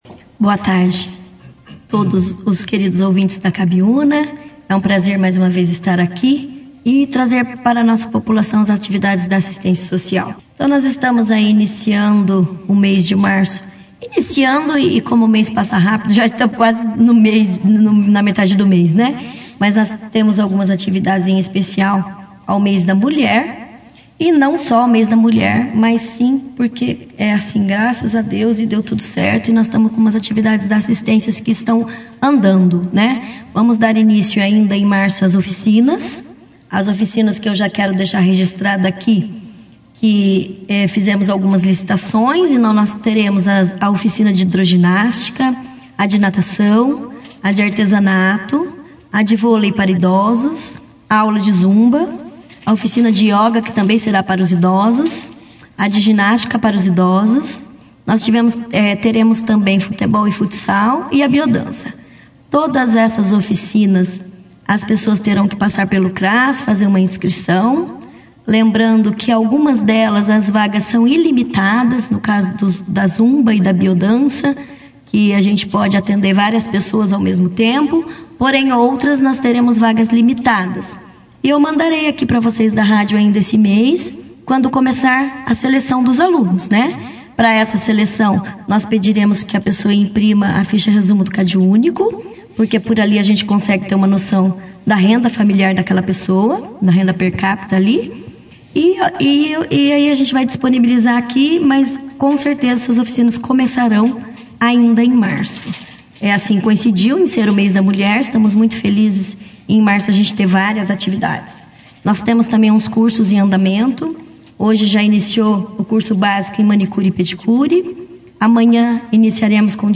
A secretária de Assistência Social e Assuntos da Família de Bandeirantes, Rosiane Cristiane Vieira Néia Storti, (foto), participou da segunda edição do Jornal Operação Cidade desta segunda-feira, 9 de março, onde falou sobre diversas ações desenvolvidas pela secretaria ao longo deste ano.
acao-social-entrevista-da-secretaria-09-de-marco.mp3